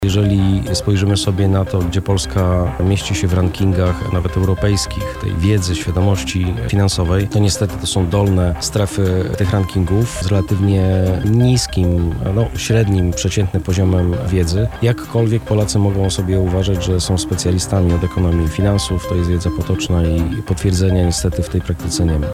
[PORANNA ROZMOWA] Finanse w rękach młodych – czas na Global Money Week!